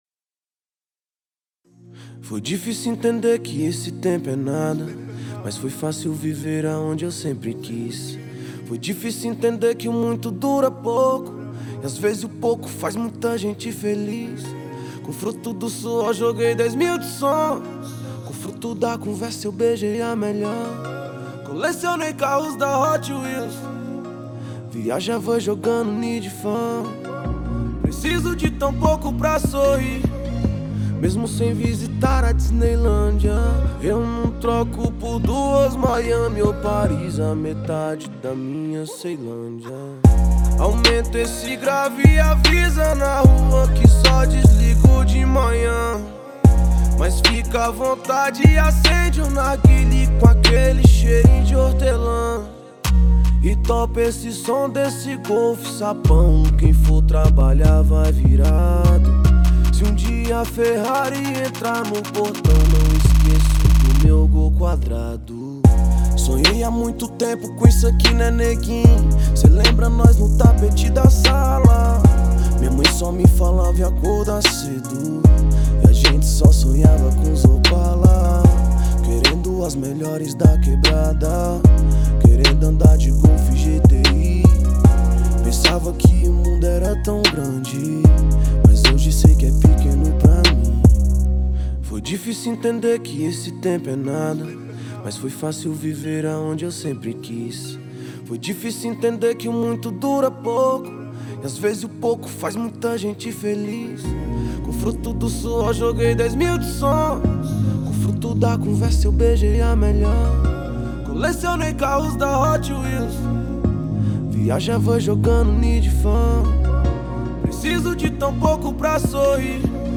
2025-02-24 22:21:30 Gênero: Hip Hop Views